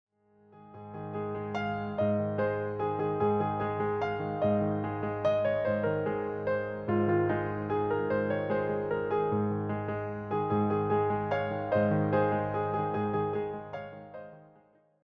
piano arrangements centered on winter and seasonal themes
calm, steady piano pieces